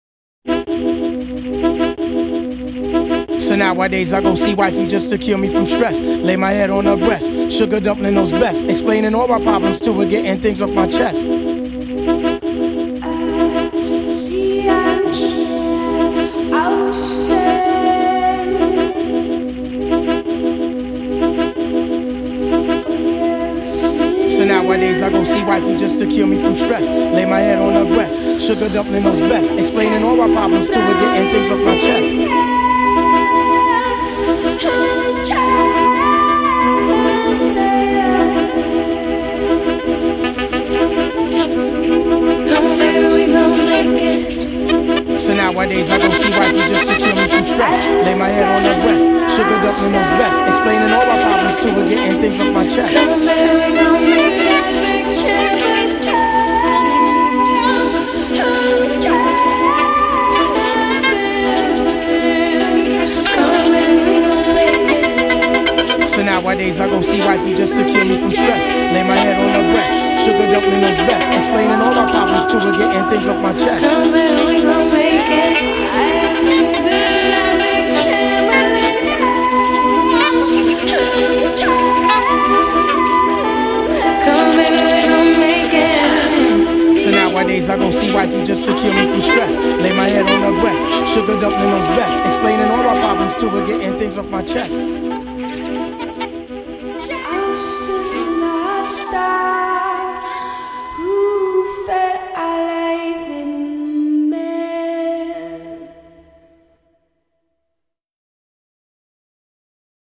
рэп-группы
Saxophone
Правда, к сожалению, здесь не вся песня :(((